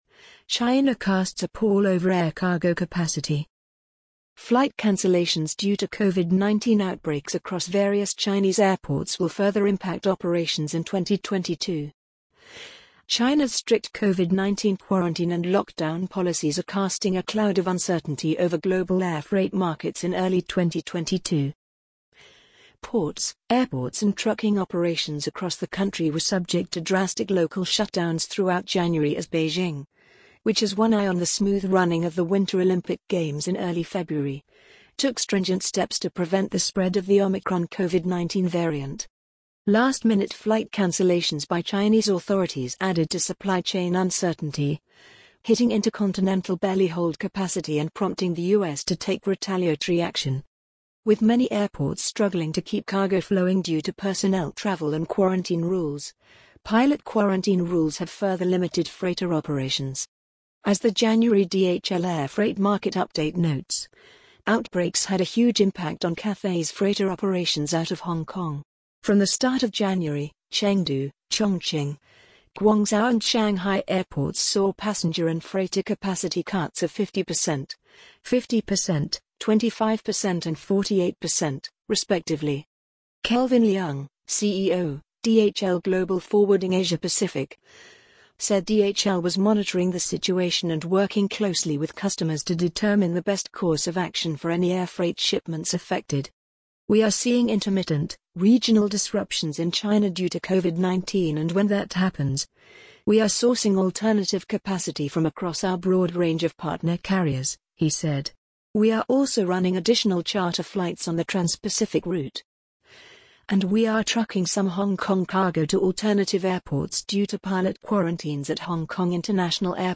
amazon_polly_27937.mp3